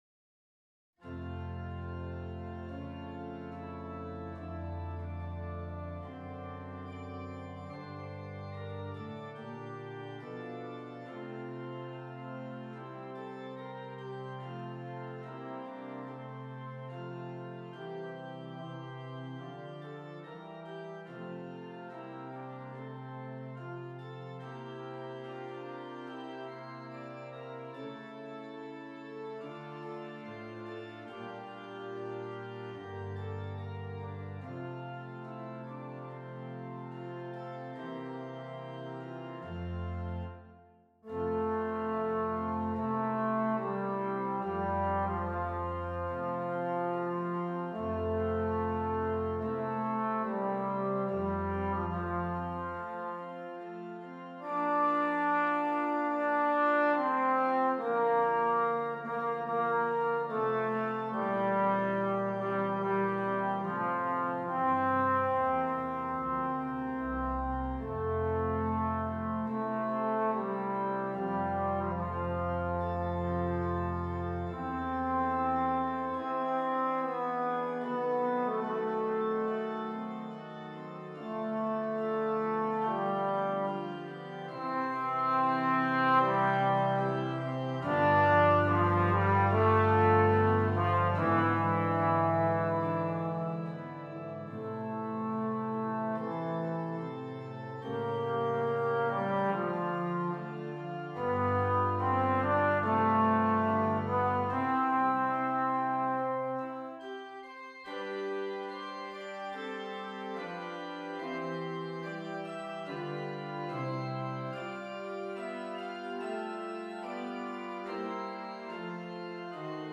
Trombone and Keyboard